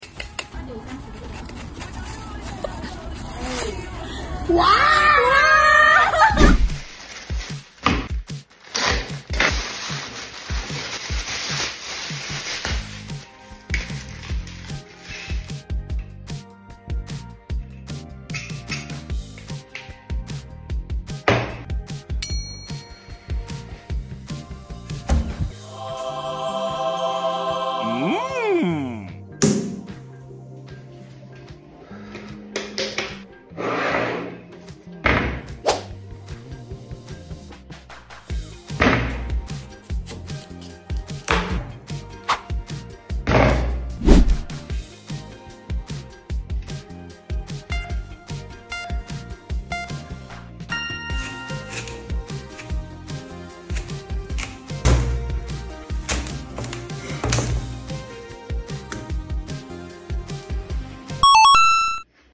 Watermelon Slicer 🍉 Sound Effects Free Download